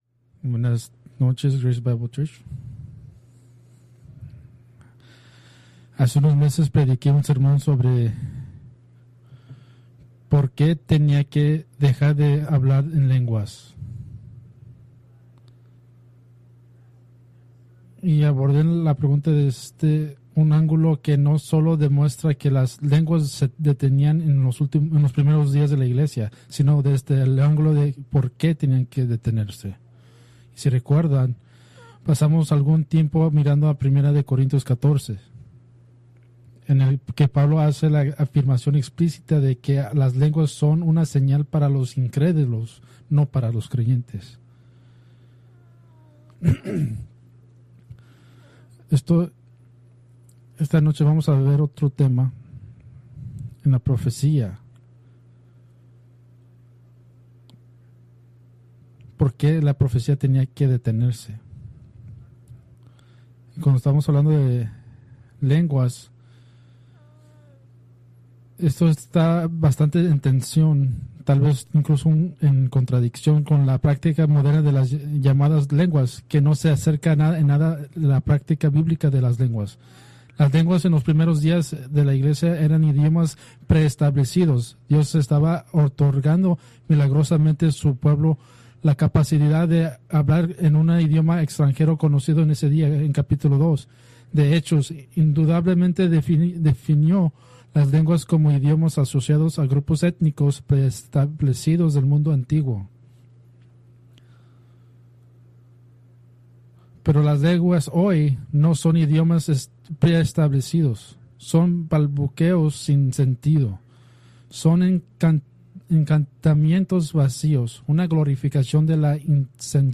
Preached October 26, 2025 from Selected Scriptures